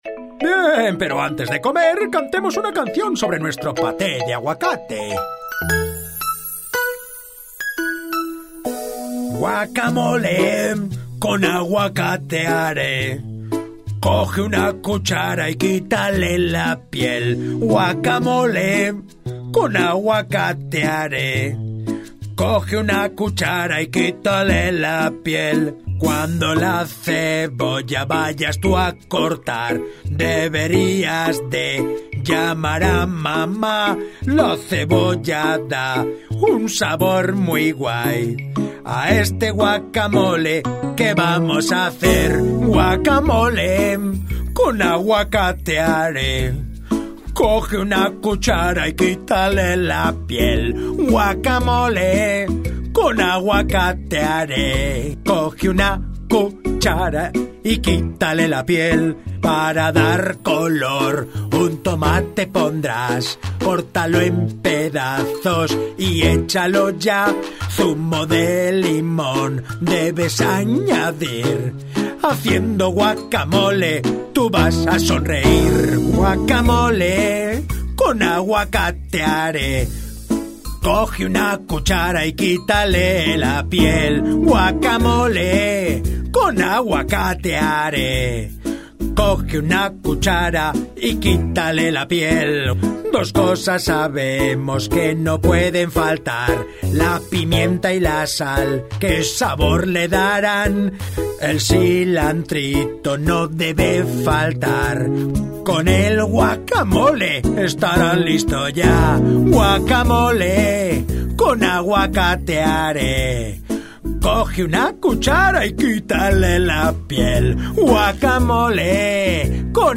voz española versatil , calida ,experiencia en narracion , e-learning , publicidad y doblaje
kastilisch
Sprechprobe: Sonstiges (Muttersprache):